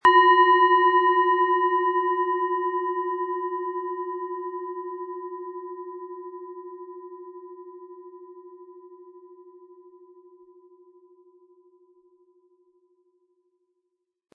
Tibetische Bauch-Kopf- und Herz-Klangschale, Ø 11,9 cm, 260-320 Gramm, mit Klöppel
Beim Aufnehmen für den Shop spielen wir die Klangschale an und probieren aus, welche Teile des Körpers aktiviert werden.
MaterialBronze